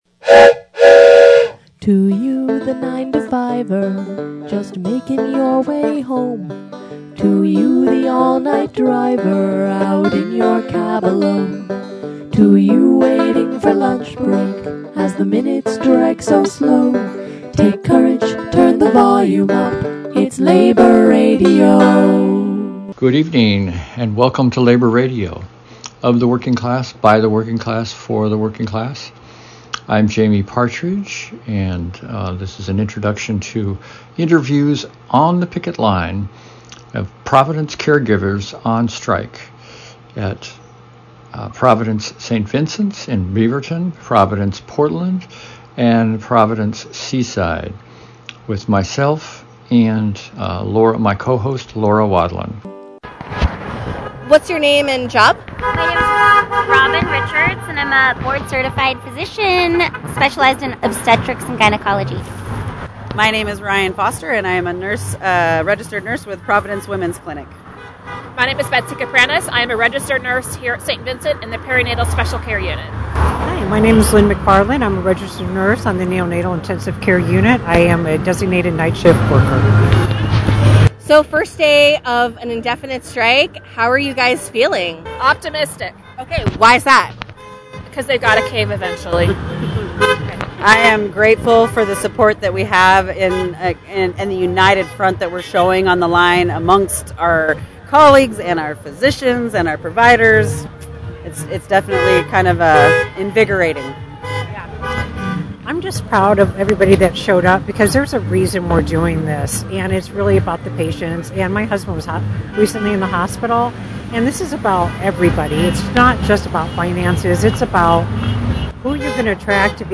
Striking nurses and doctors at Providence St. Vincent's, Providence Portland, and Providence Seaside Hospitals talk about working conditions, worker solidarity and community connections on the picket line, on day one, two and three of the open-ended strike involving over 5,000 caregivers at eight hospitals and seven clinics across Oregon, the state largest ever health care strike.
picket line interviews